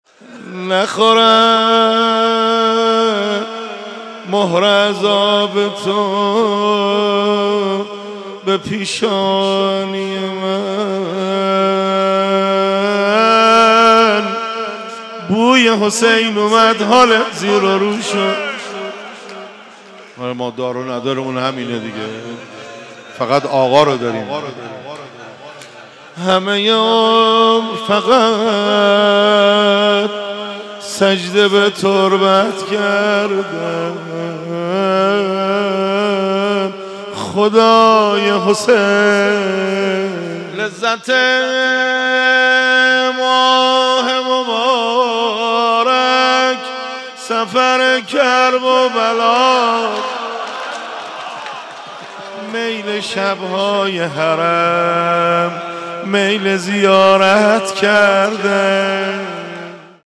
مناجات با خدا